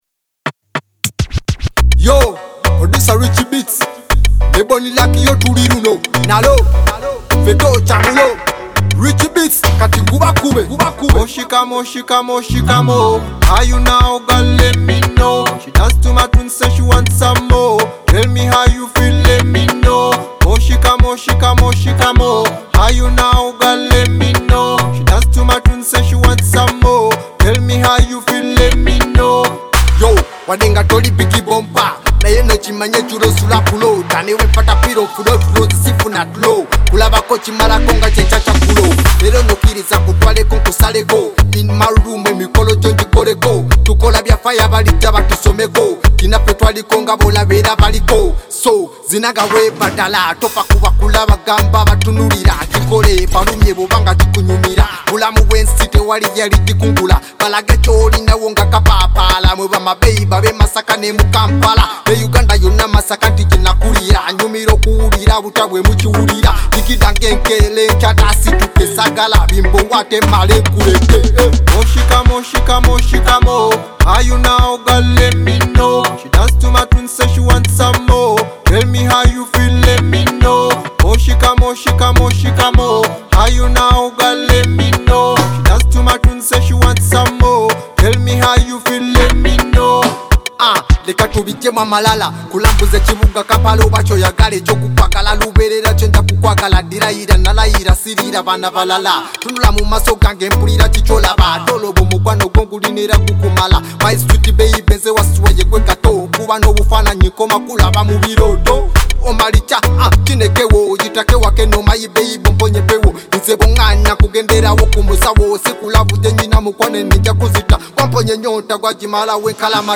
Genre: Luga Flow